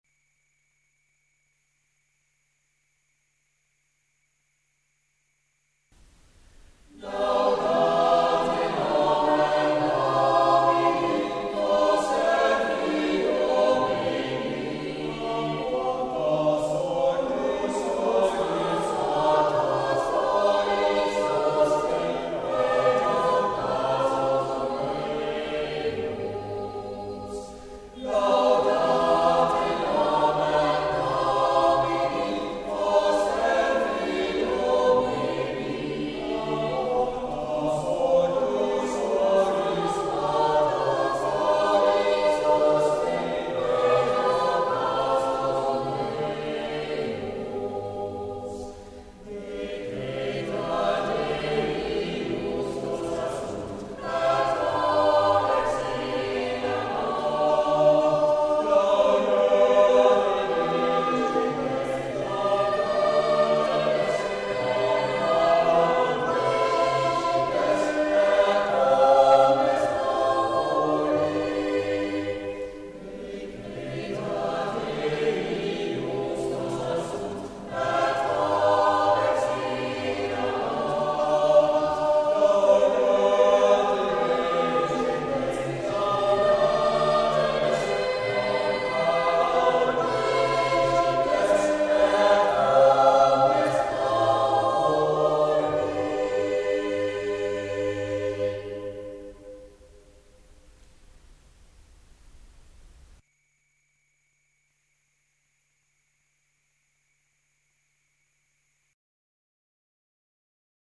van I Cantatori bestaat vooral uit polyfone muziek uit de